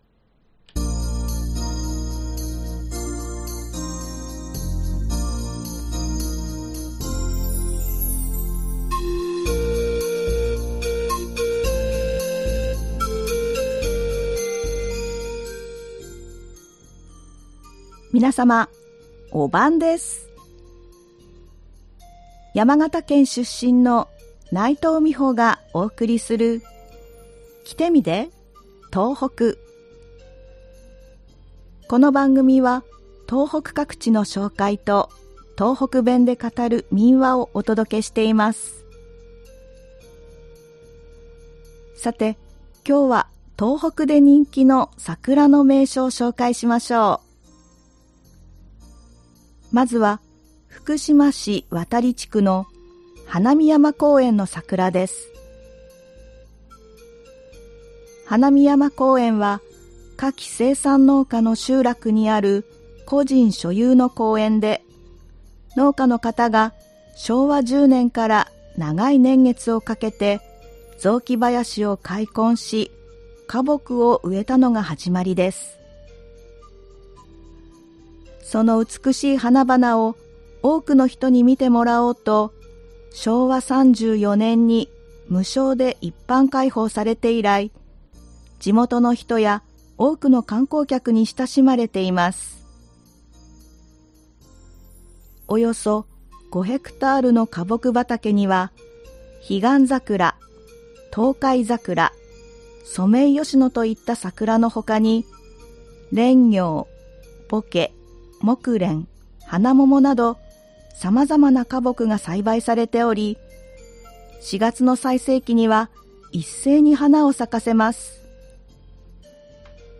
この番組は東北各地の紹介と、東北弁で語る民話をお届けしています。今日は、東北で人気の桜の名所を紹介しましょう。
ではここから、東北弁で語る民話をお送りします。今回は宮城県で語られていた民話「新徳丸」です。